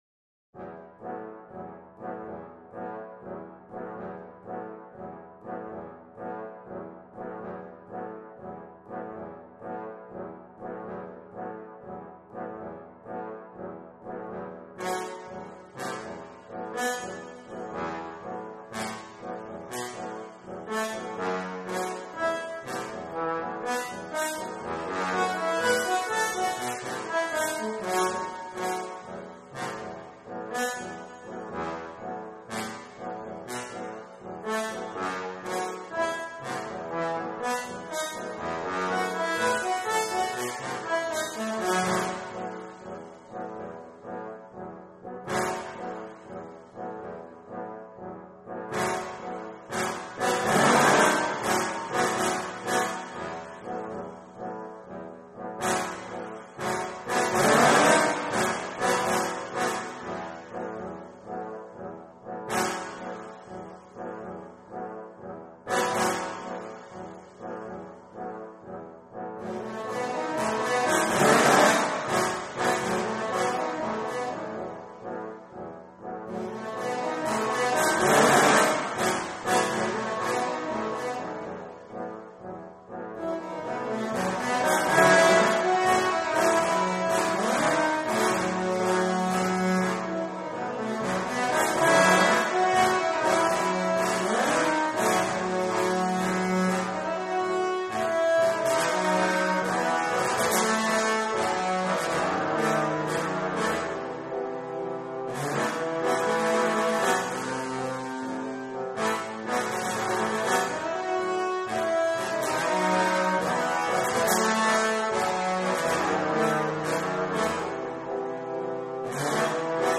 Scored for Trombone Quartet (Three Tenor, One Bass)